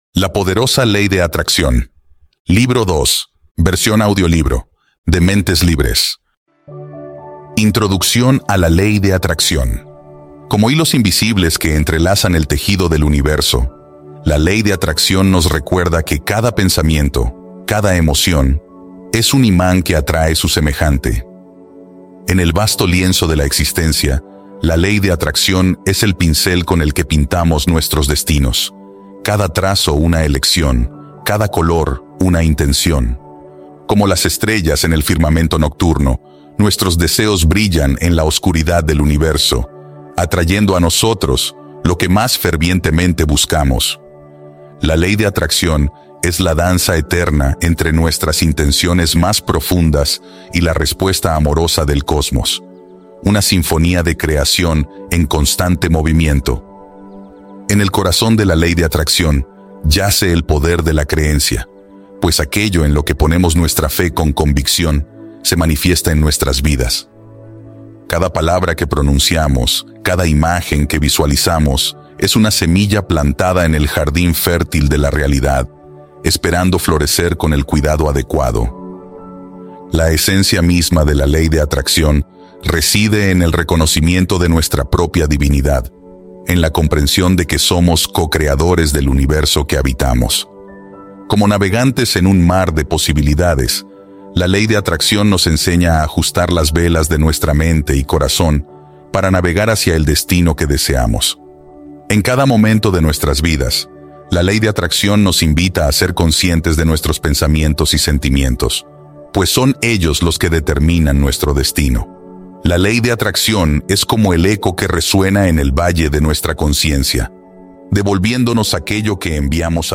AUDIOLIBRO 2: LA PODEROSA LEY DE ATRACCIÓN
DEMO-AUDIOLIBRO-2-LA-PODEROSA-LEY-DE-ATRACCION.mp3